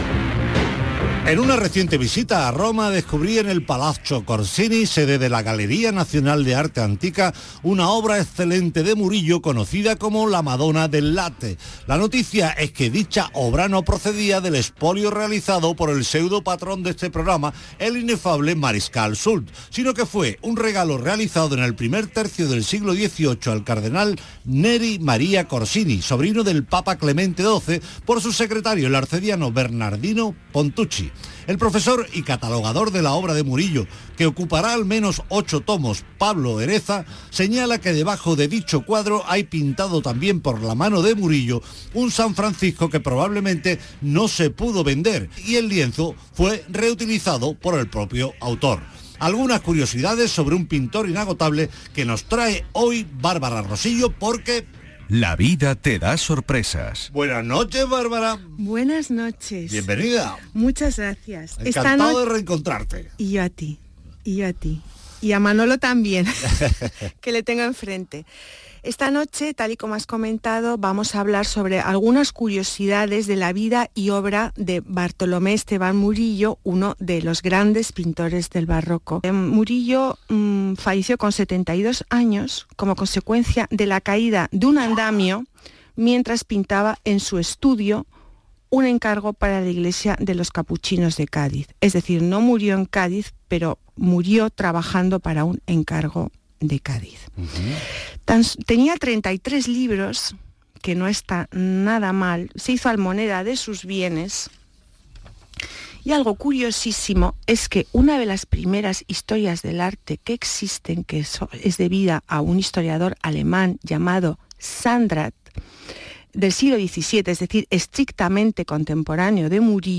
Aquí os dejo parte de mi intervención en el programa de Radio Andalucía Información, «Patrimonio andaluz» del día 15/01/2023